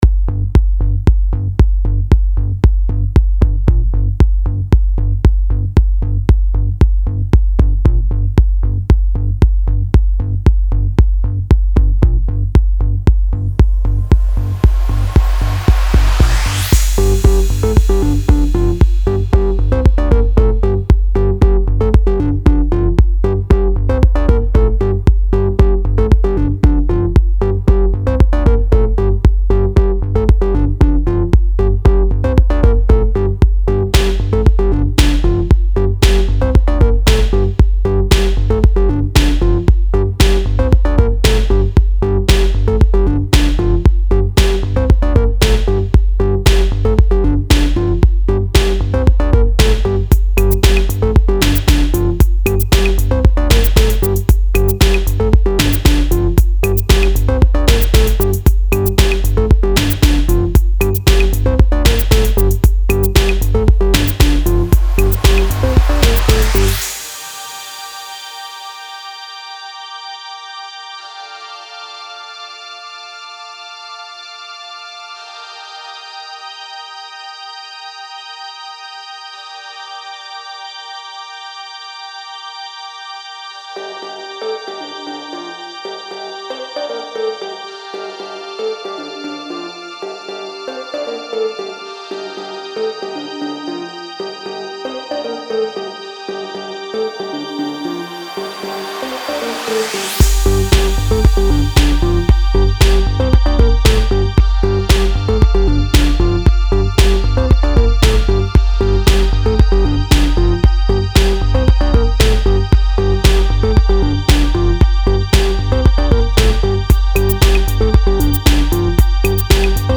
Style Style EDM/Electronic
Mood Mood Dark, Driving, Intense
Featured Featured Bass, Drums, Synth
BPM BPM 115